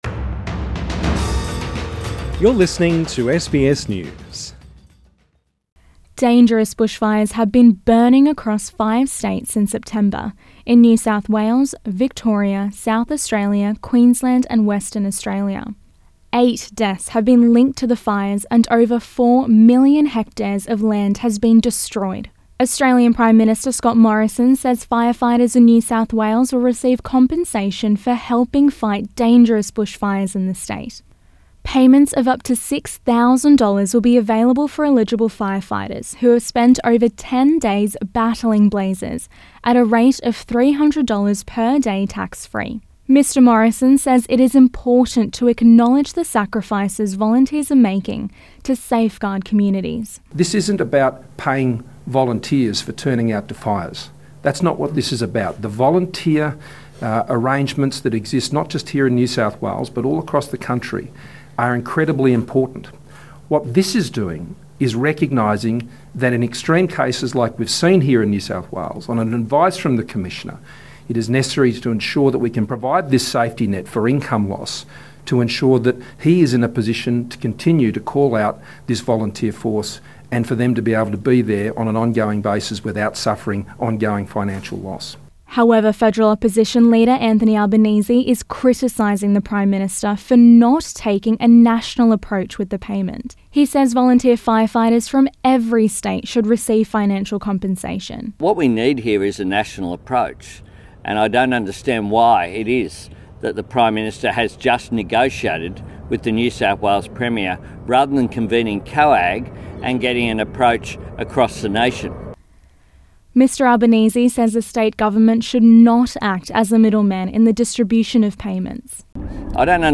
Gladys Berejiklian, Scott Morrison Shane Fitzsimmons speak to the media during a press conference at NSW Rural Fire Service (RFS) Headquarters.